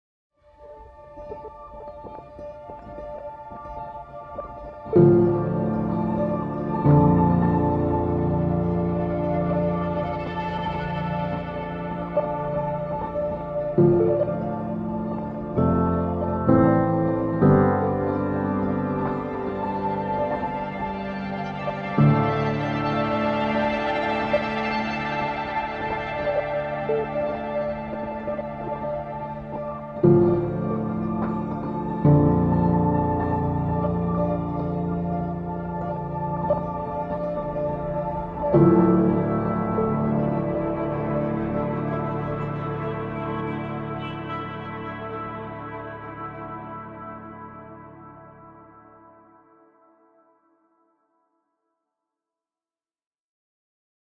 白日梦是一个私密的发行版本，其中包括四种针对情感，空灵背景的工具。
对于Cozy Piano贴片，我们在pianissimo范围内录制了立式钢琴，并在钢琴后面放置了一对立体声Neumann KM 184。如果您正在寻找一款柔和，亲切且个性恰到好处的钢琴，那就太棒了。
最后，Ether小提琴利用“在摄像机内”捕获的真实自动平移软颤音演奏的录音。
•表现得非常柔和（pianissimo范围）
•真正的物理自动平移独奏小提琴柔和的颤音
•可调节的混响海关礼堂冲动